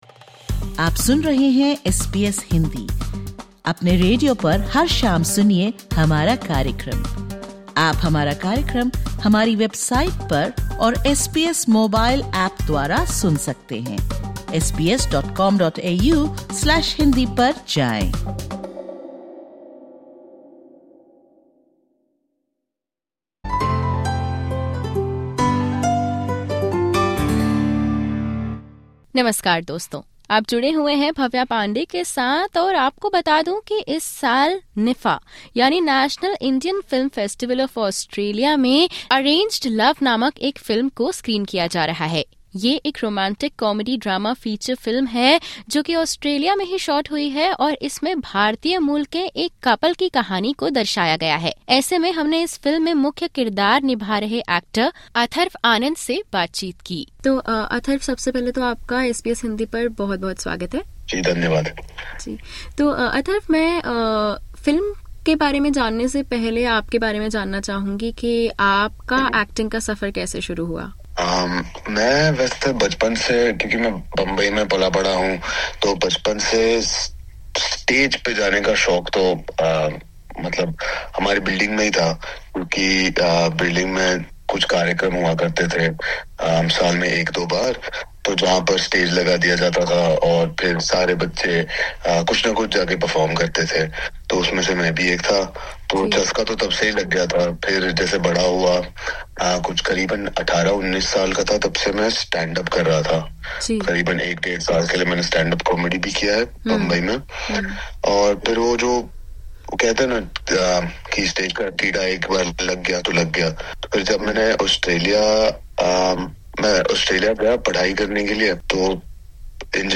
SBS Hindi recently interviewed the cast and crew of 'Arranged Love', screened at the National Indian Film Festival of Australia.